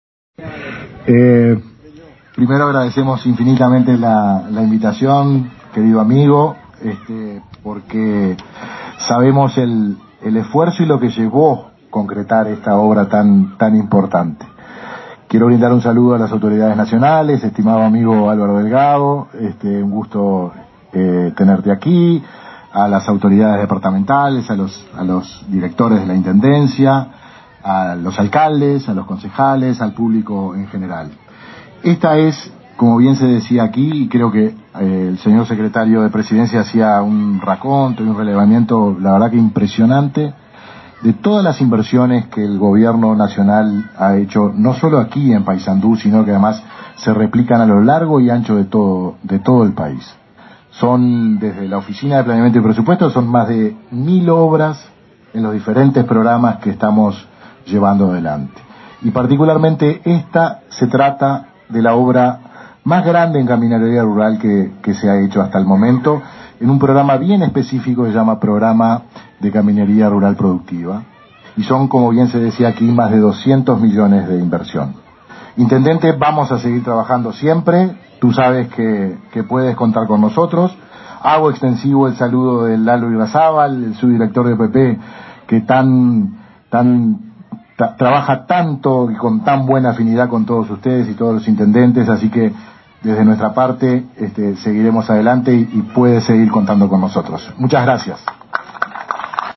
Palabras del coordinador de la OPP, Guillermo Bordoli
Este 26 de agosto fueron inauguradas las obras de pavimentación en camino Piñera-Merino- Morató, en el departamento de Paysandú.